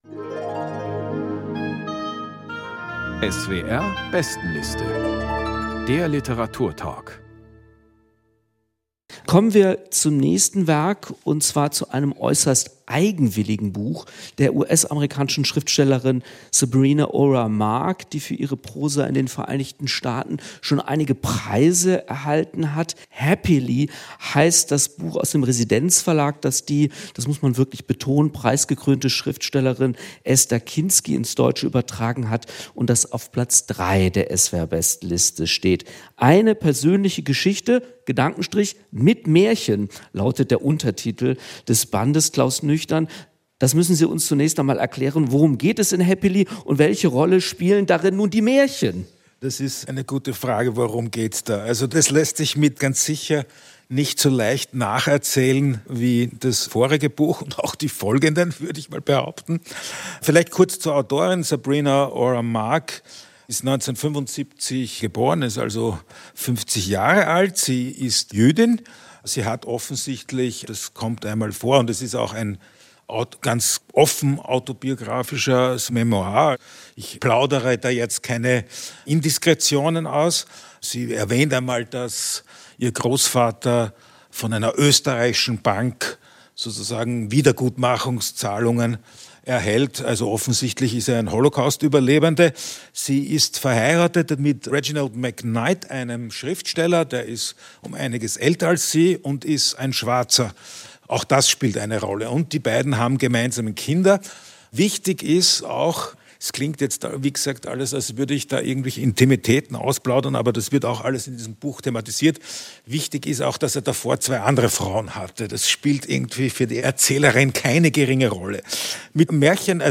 Sabrina Orah Mark: Happily | Lesung und Diskussion ~ SWR Kultur lesenswert - Literatur Podcast